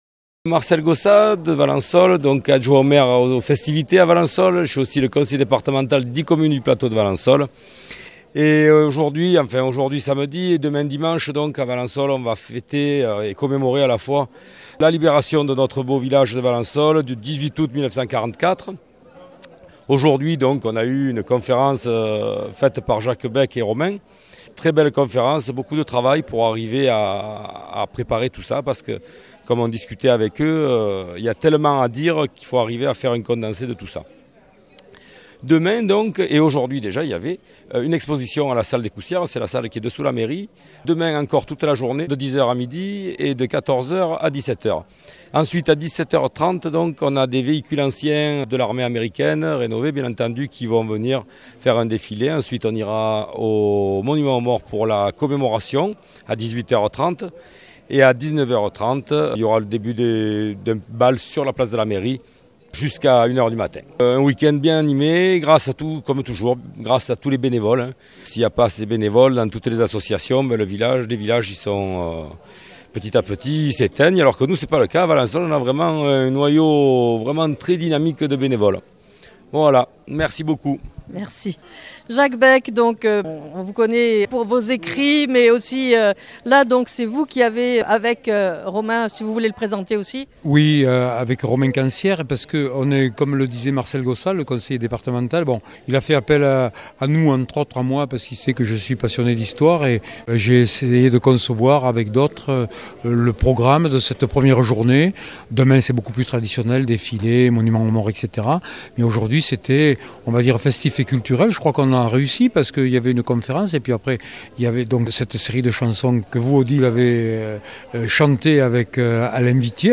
En fin d'interview